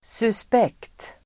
Ladda ner uttalet
suspekt adjektiv, suspicious , suspect Uttal: [susp'ek:t] Böjningar: suspekt, suspekta Synonymer: dubiös, misstänkt, skum, tvivelaktig Definition: som verkar misstänkt Exempel: en suspekt figur (a suspicious figure)